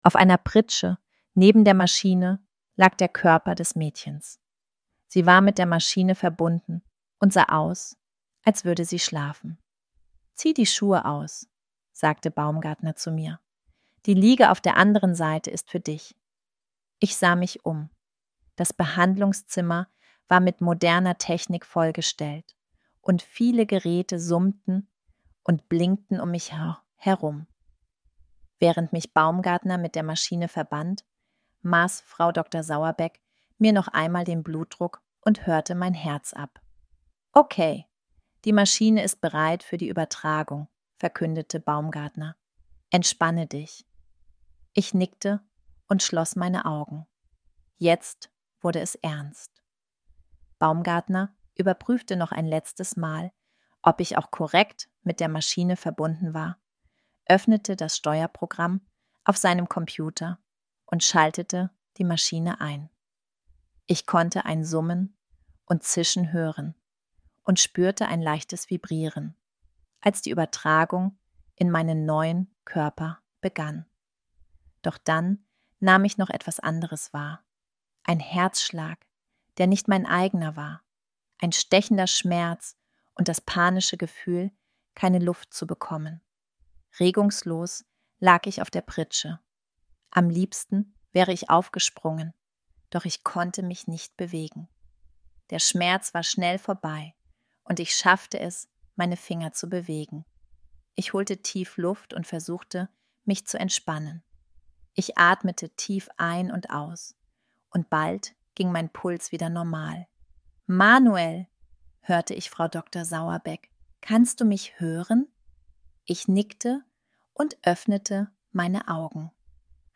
Text to Speech
Diesen Text habe ich nun von verschiedenen KI-Werkzeugen lesen lassen.
Hier habe ich die Stimmen Sabrina und Rachel gewählt.